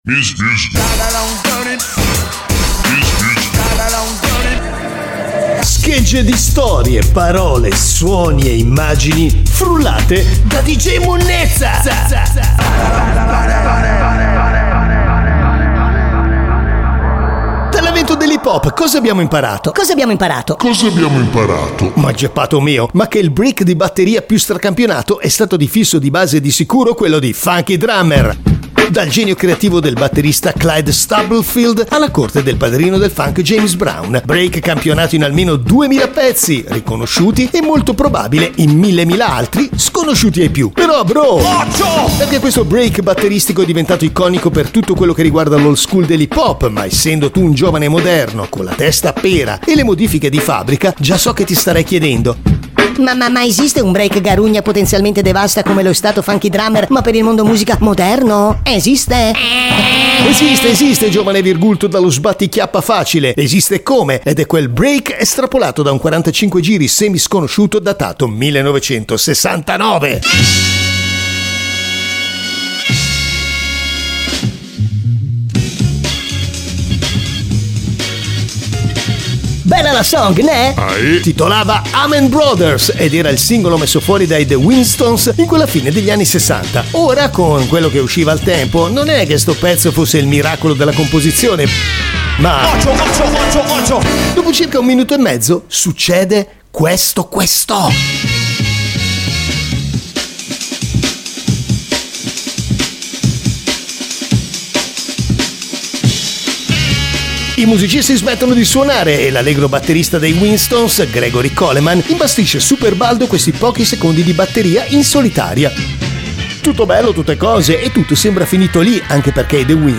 Break hip-hop
RAP / HIP-HOP